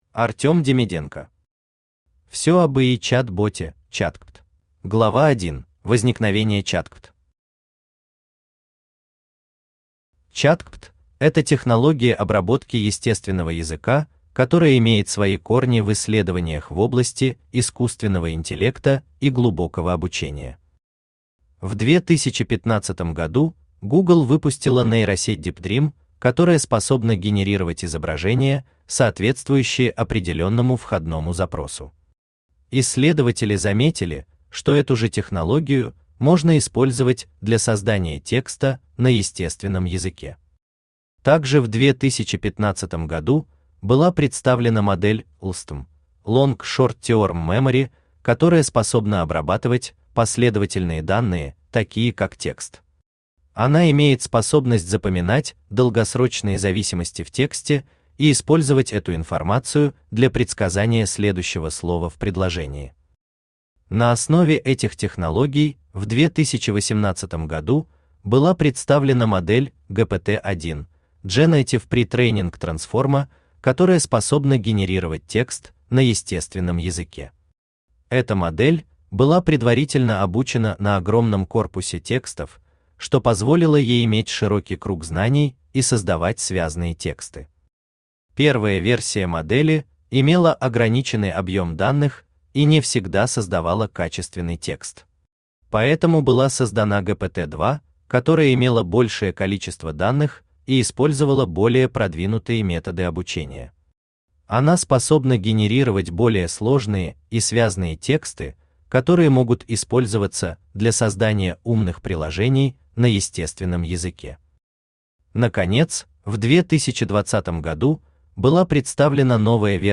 Aудиокнига Всё об ИИ чат-боте ChatGPT Автор Искусственный Интеллект Читает аудиокнигу Авточтец ЛитРес.